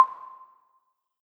heartbeat.ogg